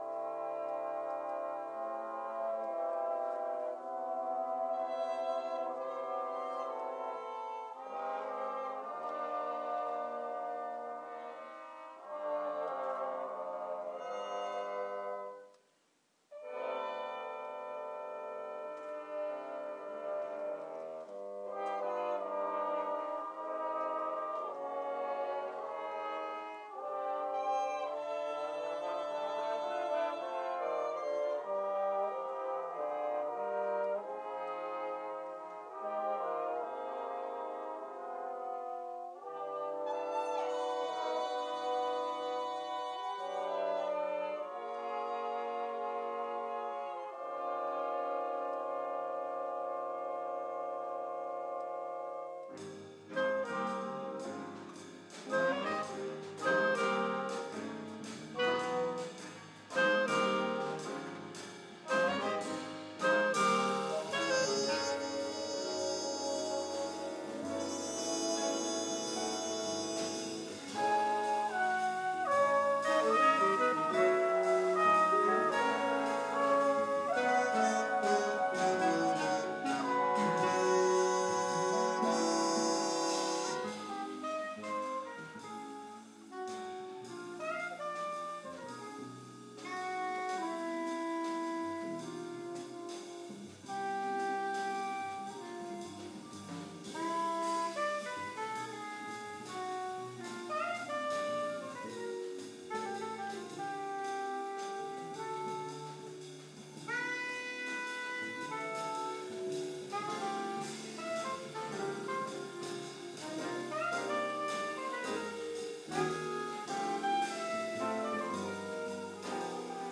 Orchestra Barga Jazz